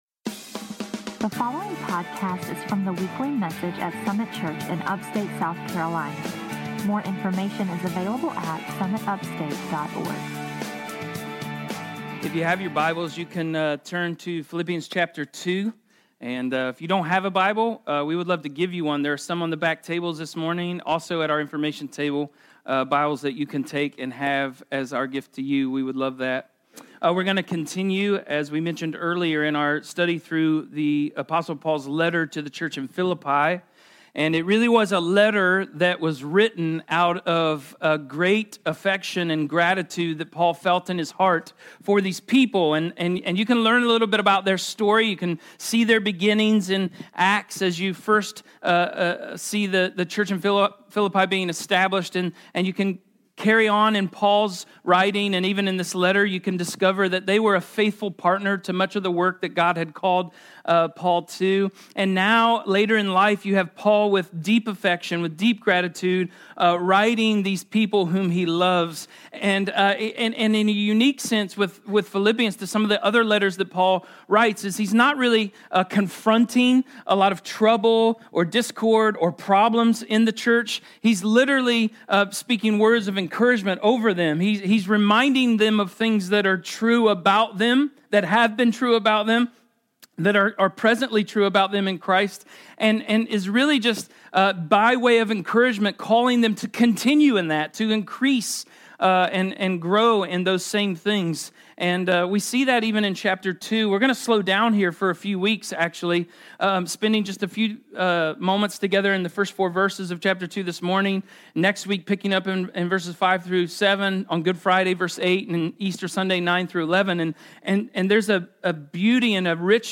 Recorded at Cherrydale